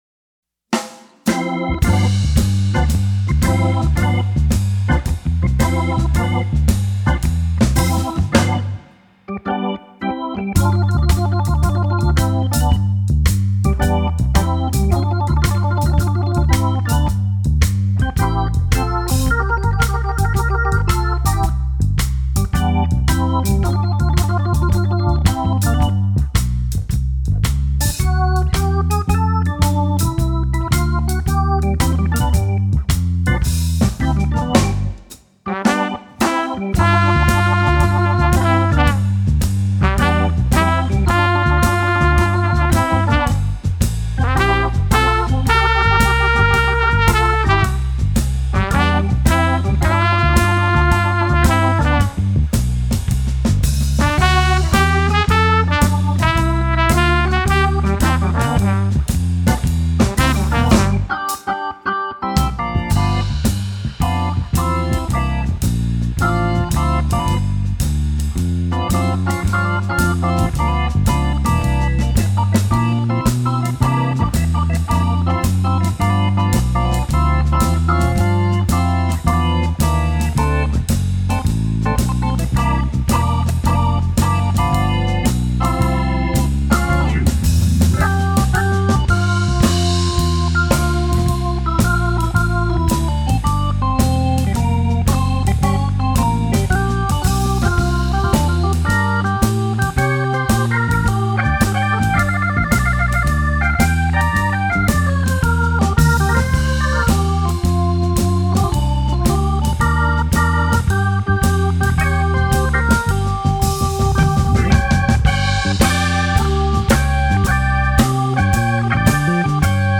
File: Funky Jazz